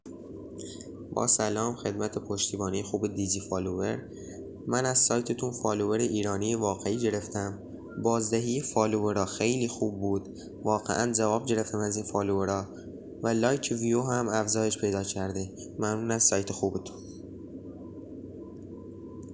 نظرات مشتریان عزیزمون با صدای خودشون